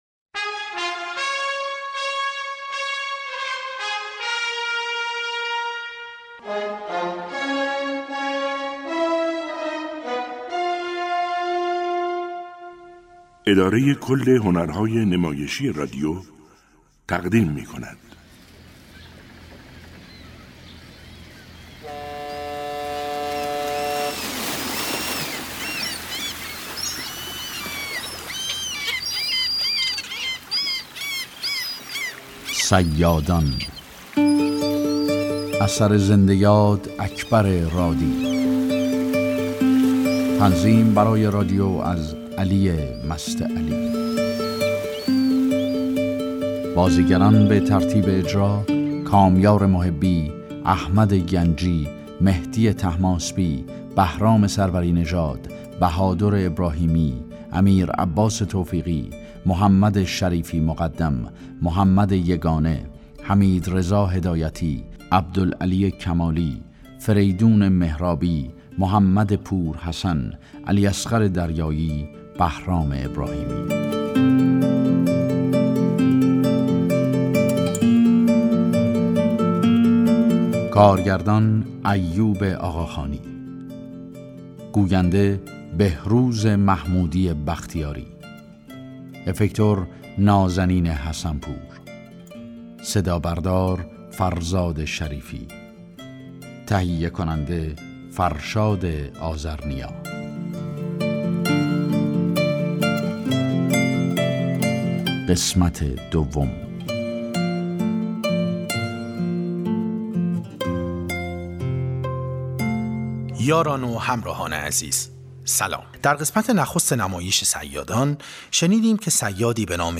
نمایش رادیویی «صیادان»